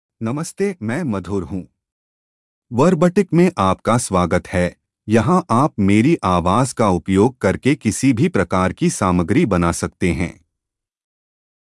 Madhur — Male Hindi (India) AI Voice | TTS, Voice Cloning & Video | Verbatik AI
MadhurMale Hindi AI voice
Madhur is a male AI voice for Hindi (India).
Voice sample
Listen to Madhur's male Hindi voice.
Male
Madhur delivers clear pronunciation with authentic India Hindi intonation, making your content sound professionally produced.